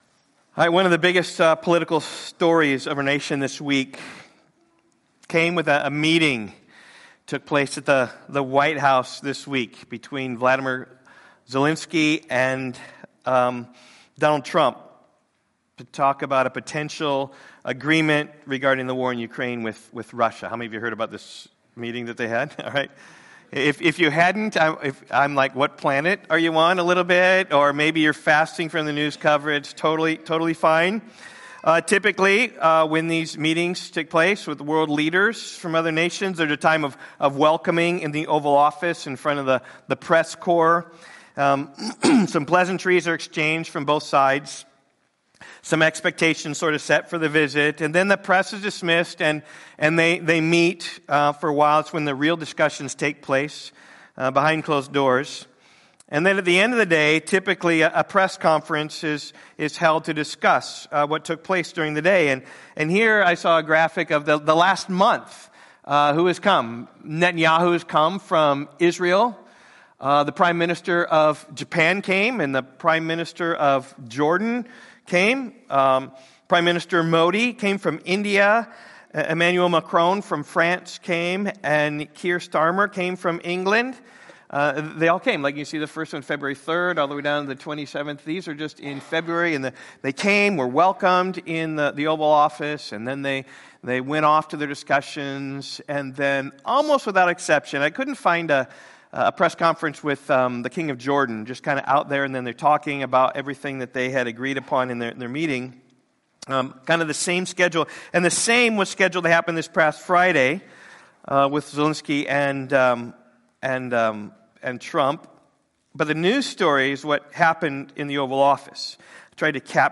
The Ethics Of Eschatology (Part 4) - Isaiah Rock Valley Bible Church Sermons podcast To give you the best possible experience, this site uses cookies.